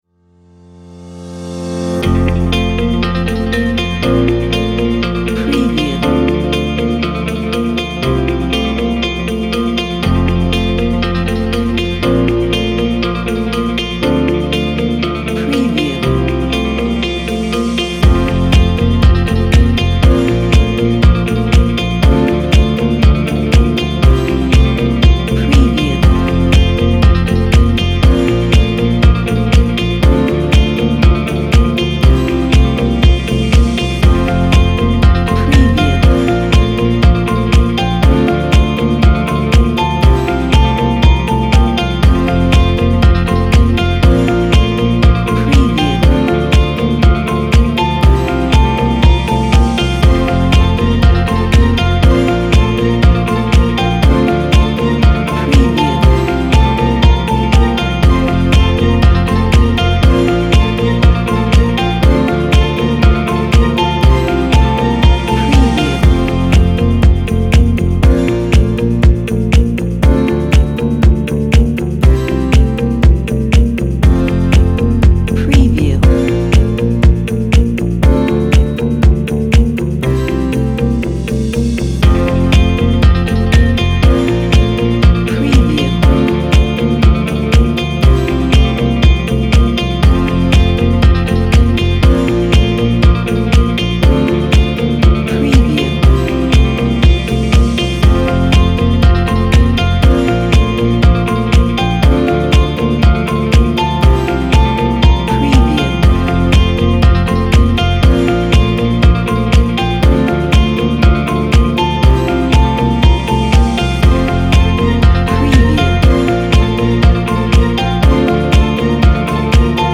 آهنگ پس زمینه تیزر تبلیغاتی